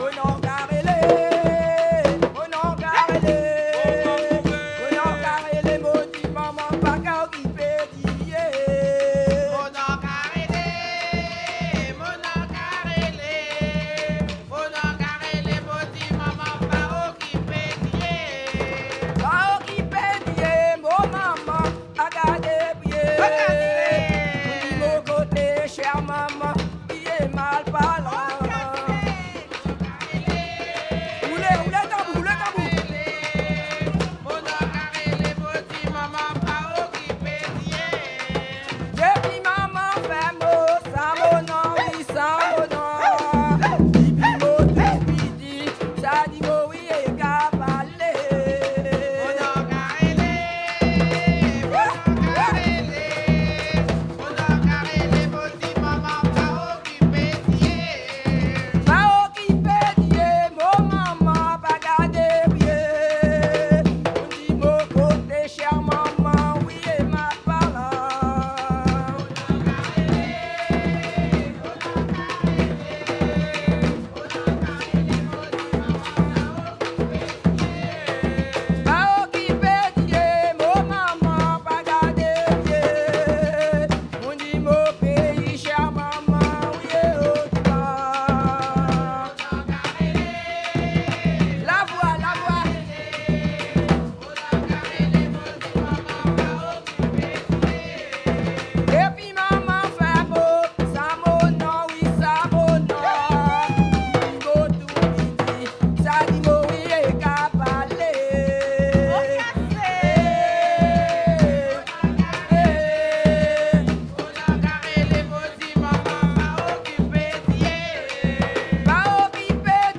danse : kasékò (créole)
Pièce musicale inédite